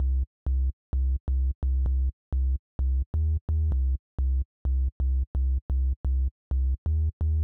INT Bass Riff C-Eb.wav